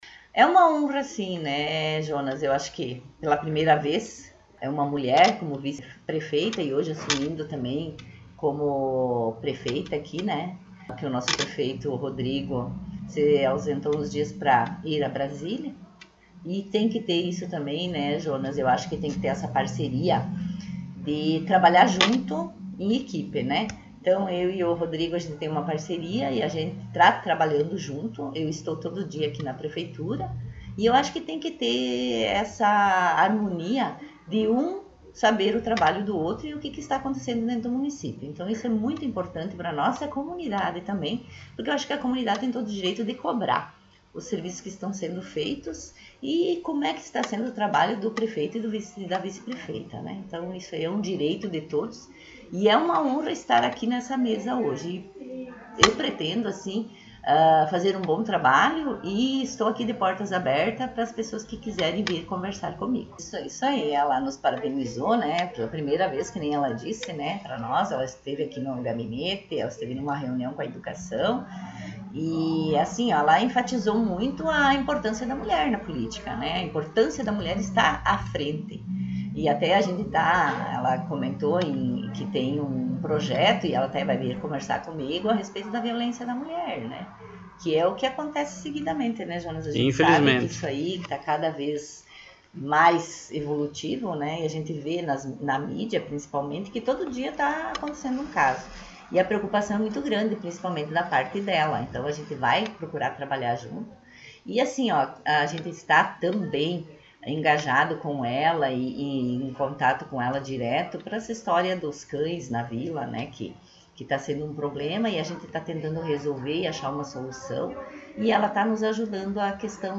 Prefeita em Exercício Marta Mino concedeu entrevista